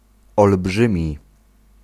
Ääntäminen
France: IPA: /e.nɔʁm/